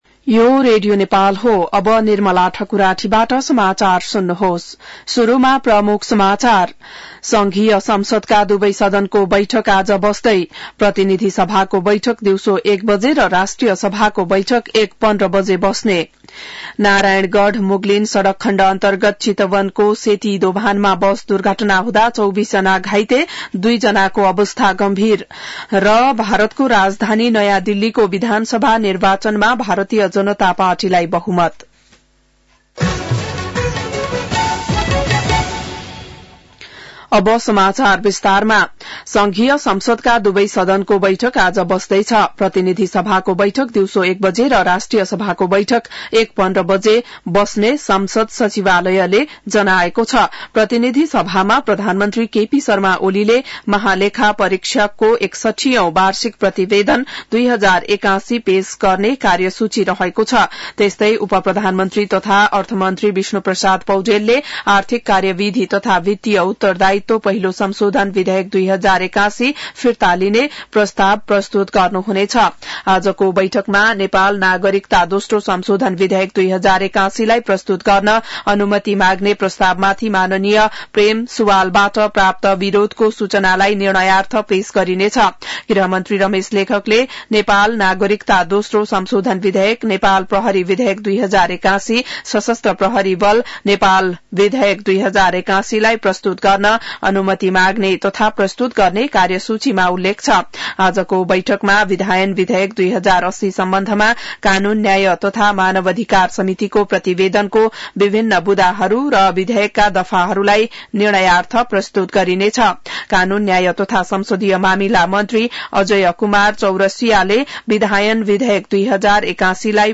बिहान ९ बजेको नेपाली समाचार : २८ माघ , २०८१